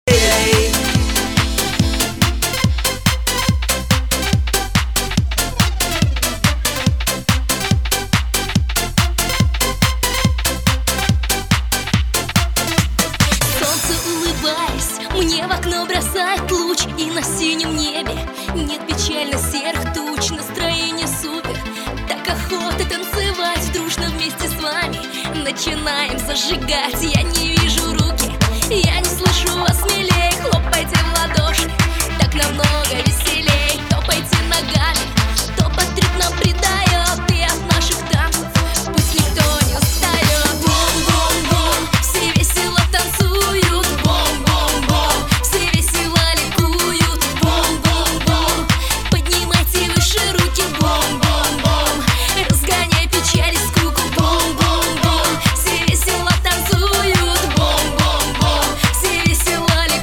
Pop
российская поп-группа.